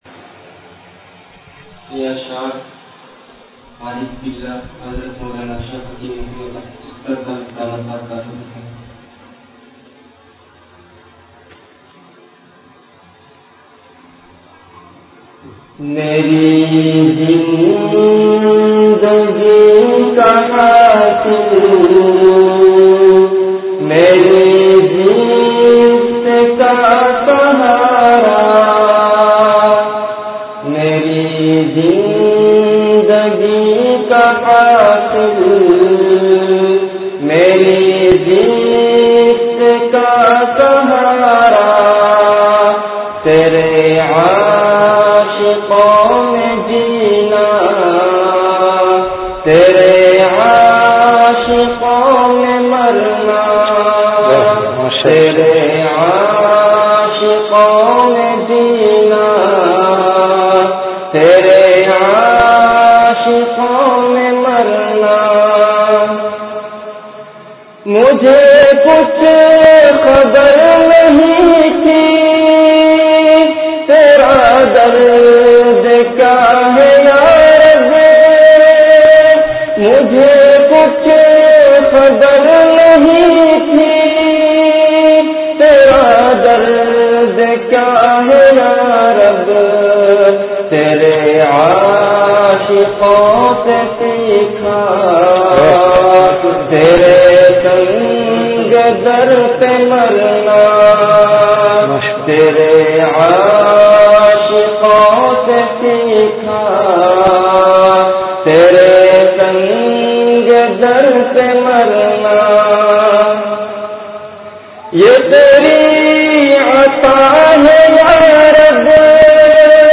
Delivered at Home.
Category Majlis-e-Zikr
Event / Time After Isha Prayer